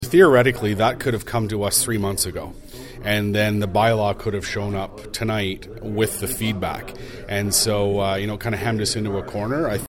Planning Chair Councillor Paul Carr tells Quinte News it should have been done in two steps, making it easier for a discussion on the fees.